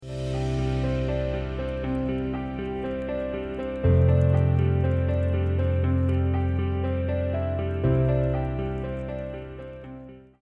The warm campfire flames 🔥 sound effects free download